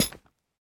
latest / assets / minecraft / sounds / block / chain / step1.ogg